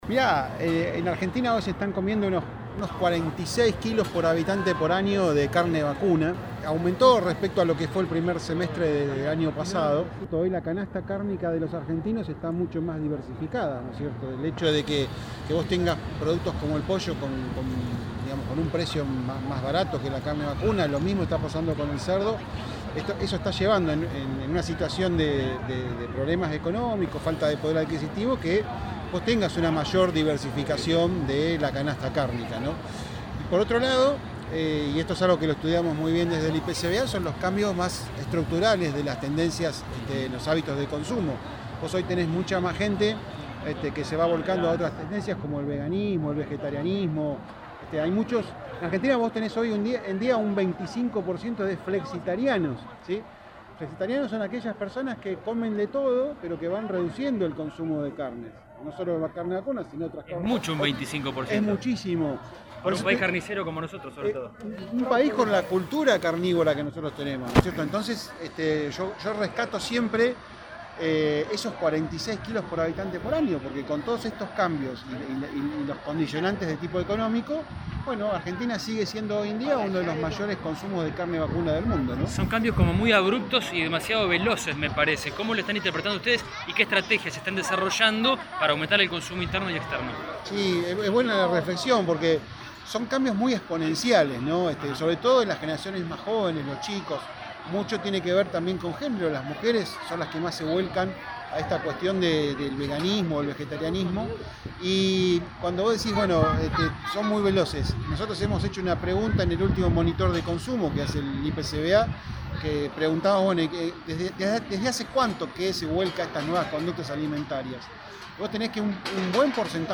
El IPCVA tuvo nuevamente este año una destacada participación en la Exposición Rural de Palermo. En diálogo con EL CAMPO HOY, remarcaron los desafíos que imponen los mercados.